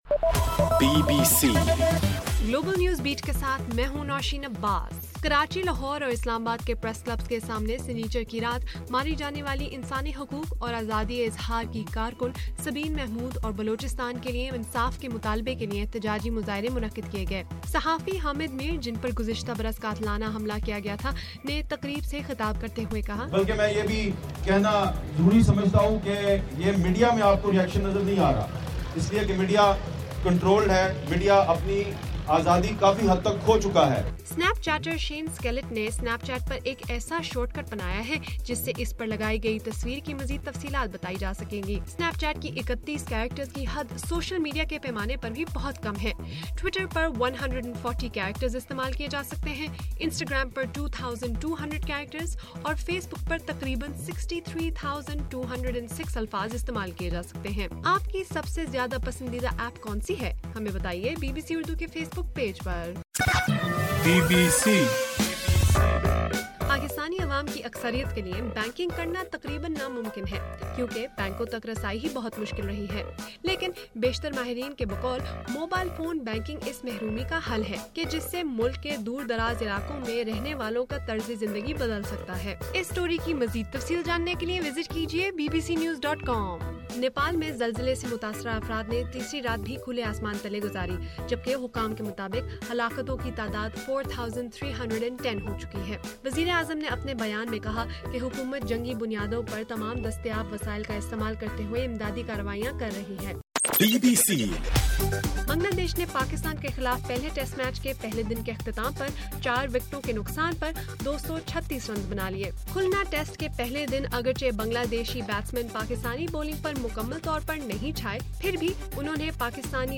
اپریل 28: رات 10 بجے کا گلوبل نیوز بیٹ بُلیٹن